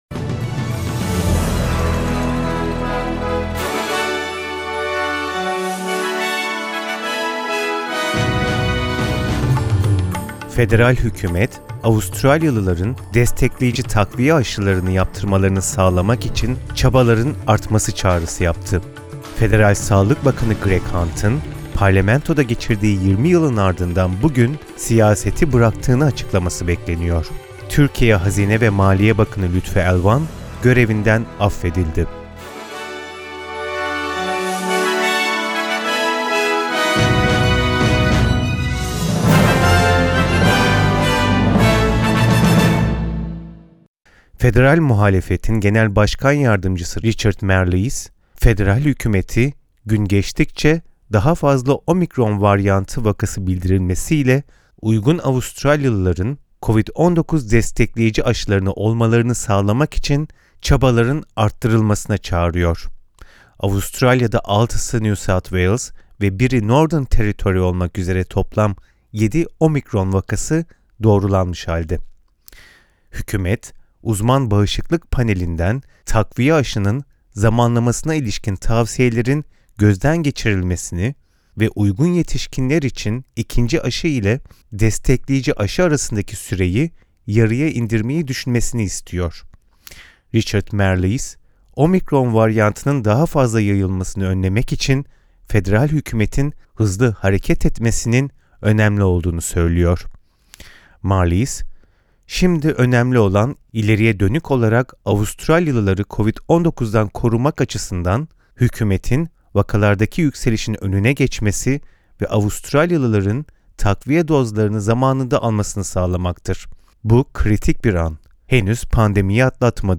SBS Türkçe Haberler 2 Aralık